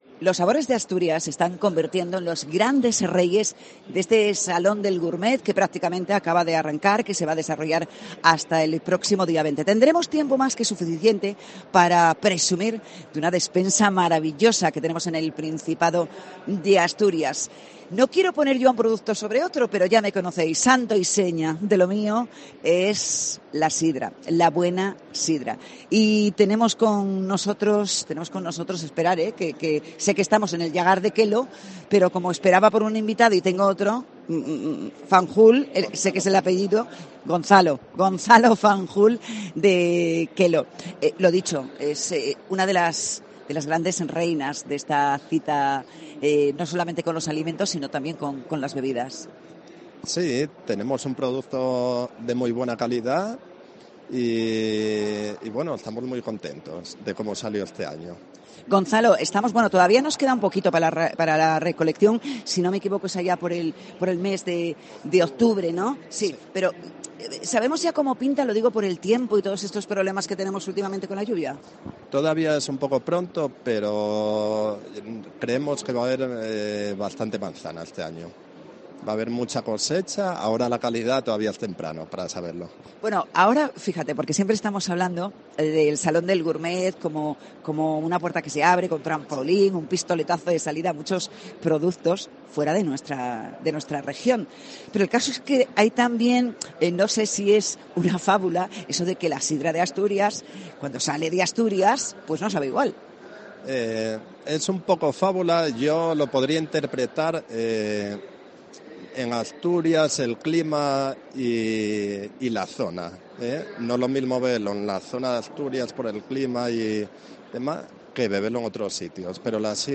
Salón Gourmets 2023: entrevista